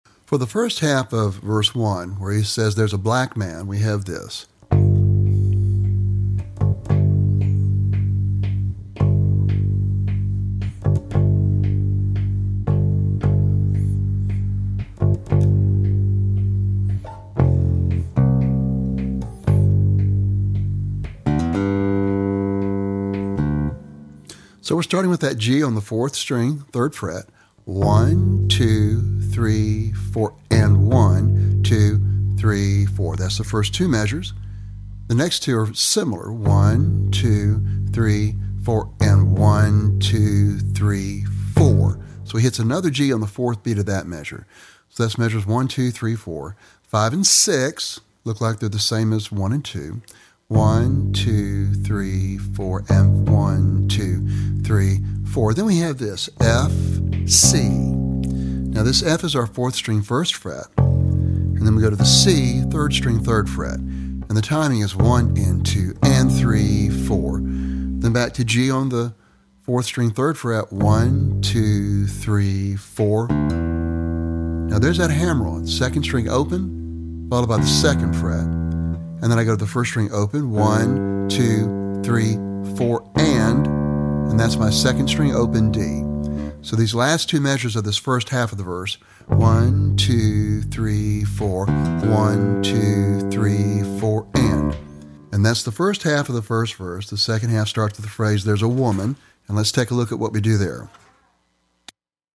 (Bass Guitar)
Lesson Sample
For Bass Guitar.